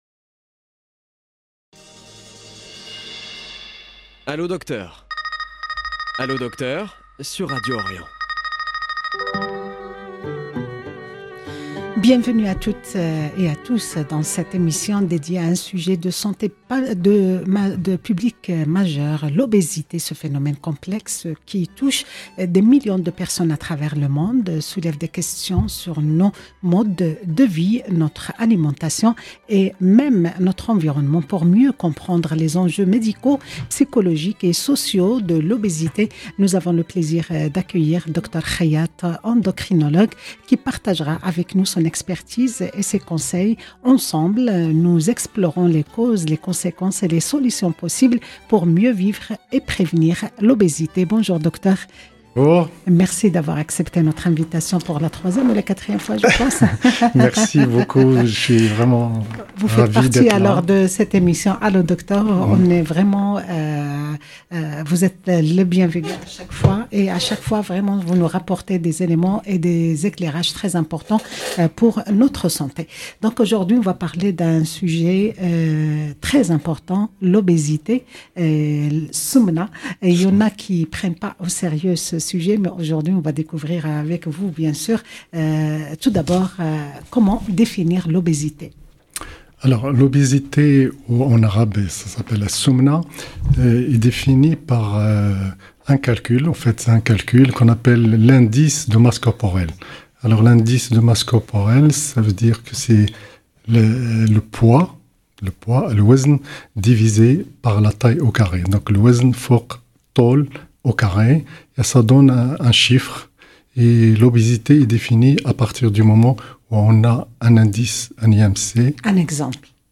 endocrinologue.